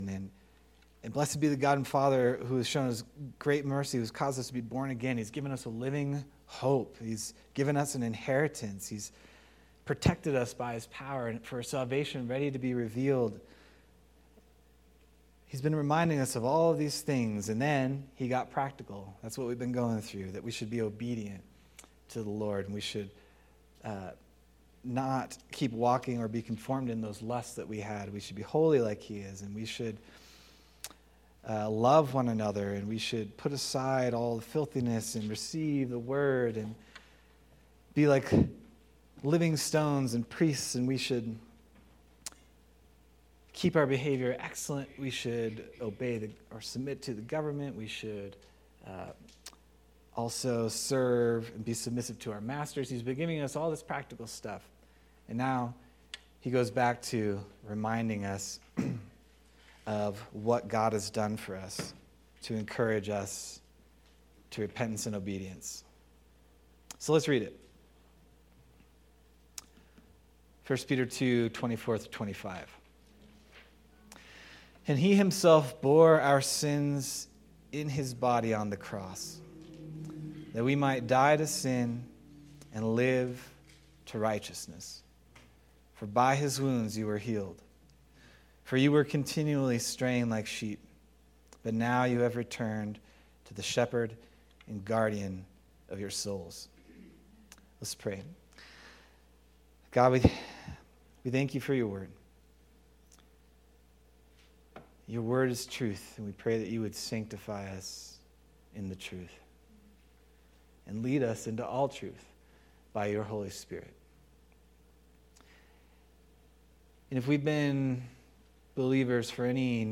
March 30th, 2025 Sermon